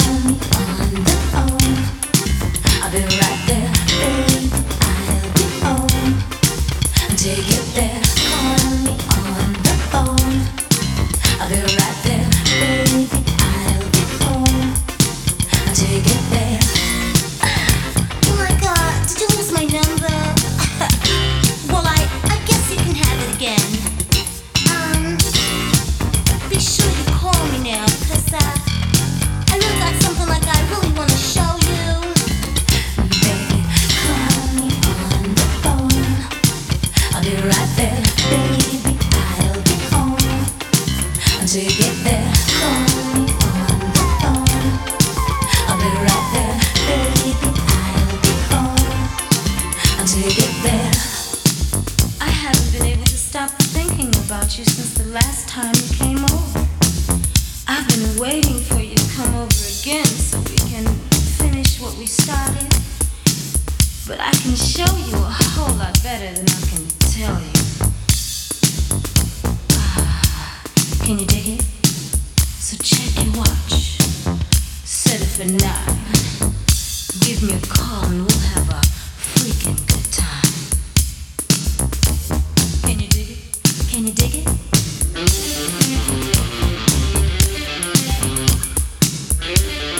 ジャンル(スタイル) DISCO / SOUL / FUNK